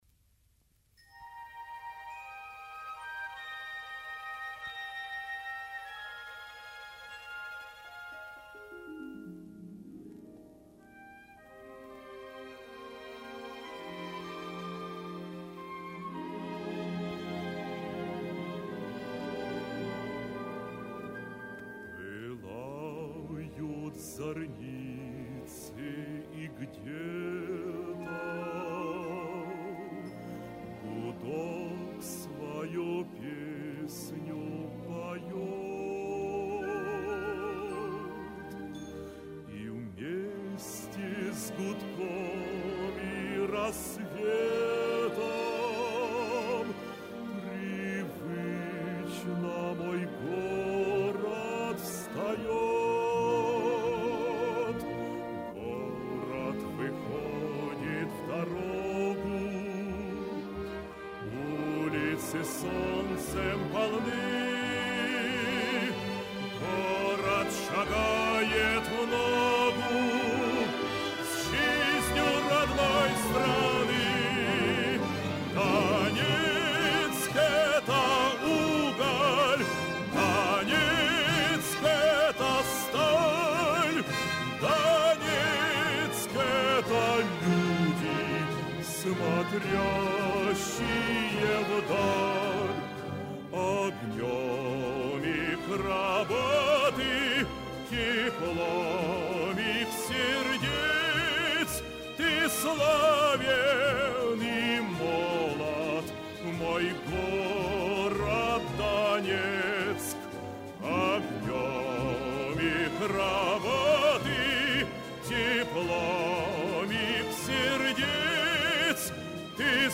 Солисты: